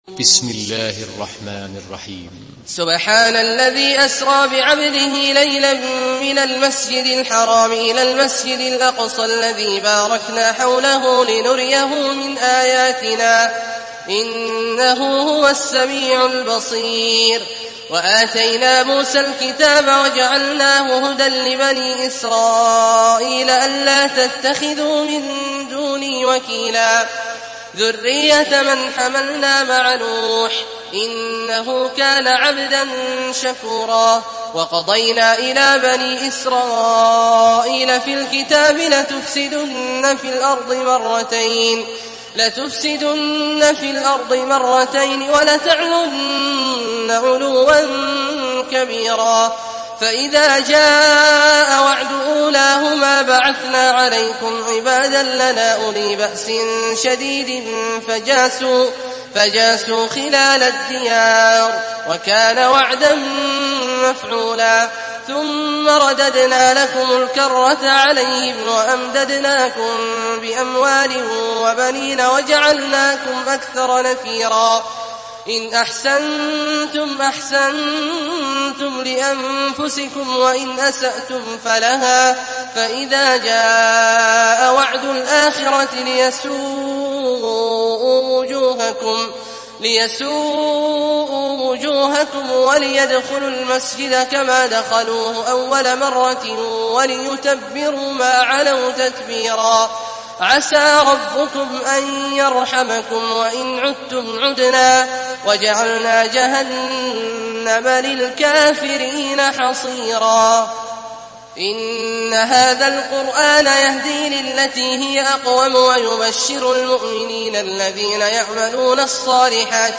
Surah Isra MP3 by Abdullah Al-Juhani in Hafs An Asim narration.
Murattal Hafs An Asim